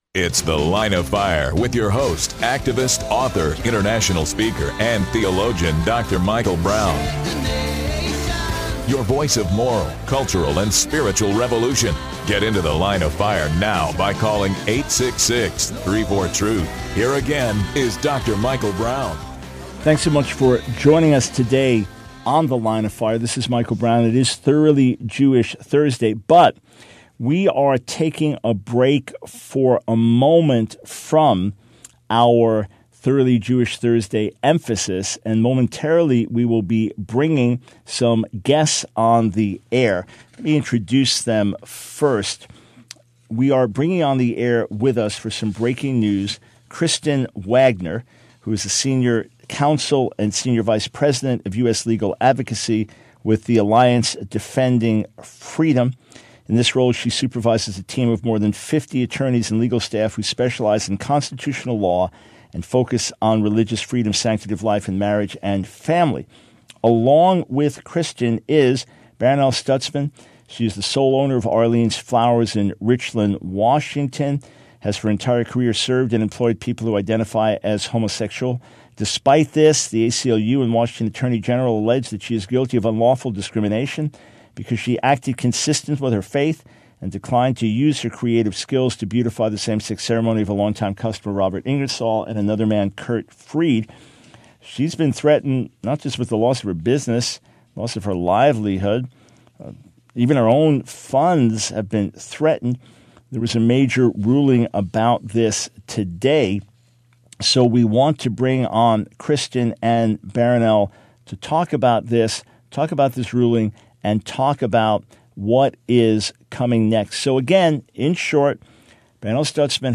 Here is the portion of the program carrying the interview.